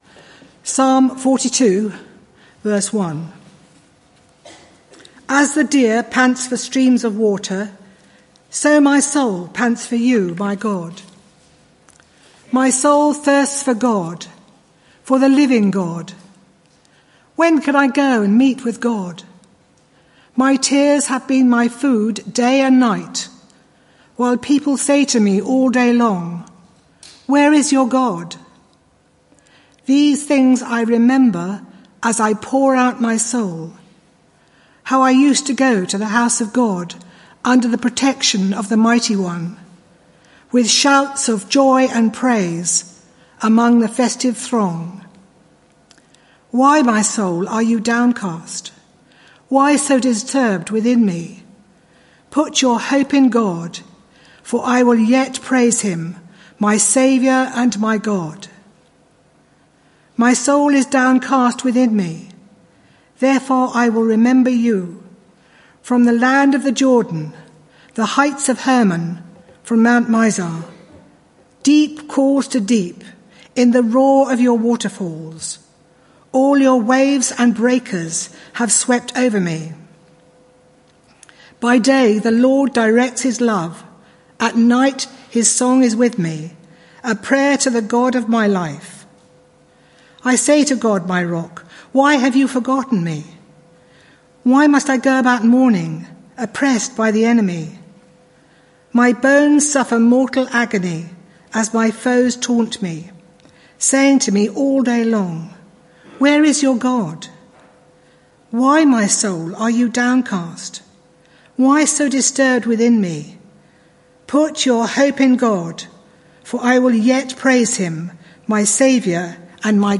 This sermon is part of a series: 24 June 2018